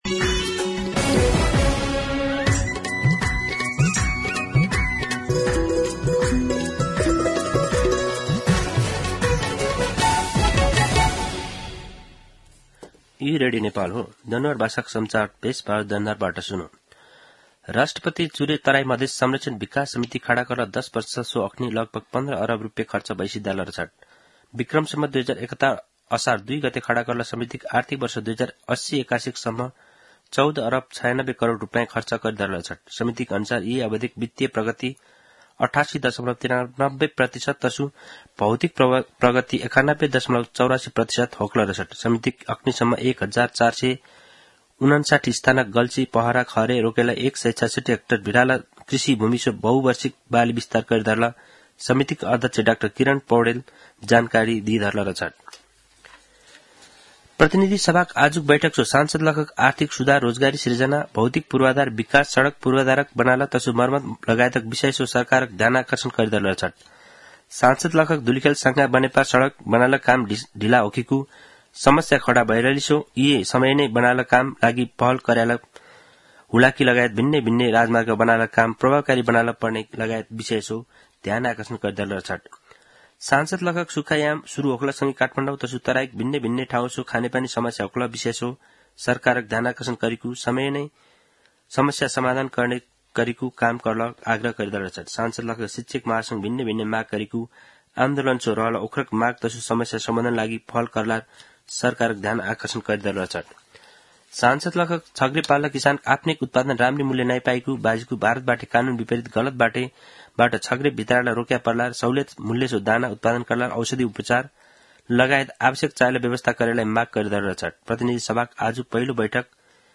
दनुवार भाषामा समाचार : २९ फागुन , २०८१
Danuwar-News-11-28.mp3